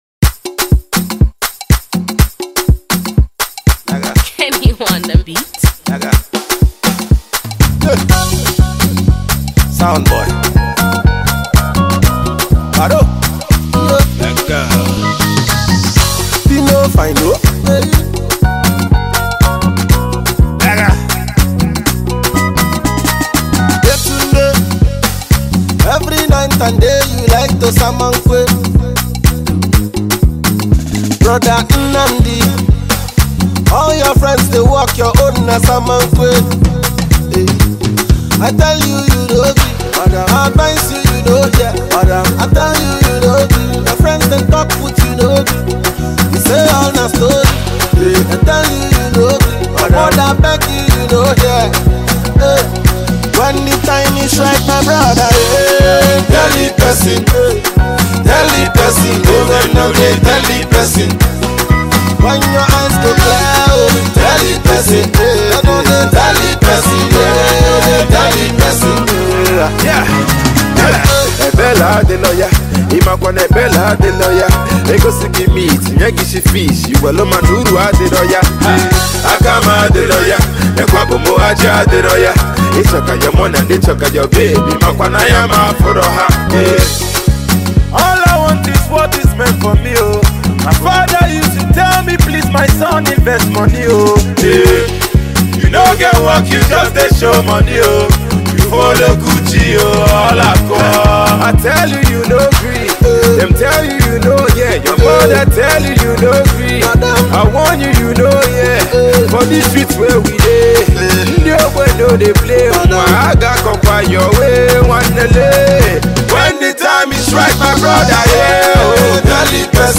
African dancehall king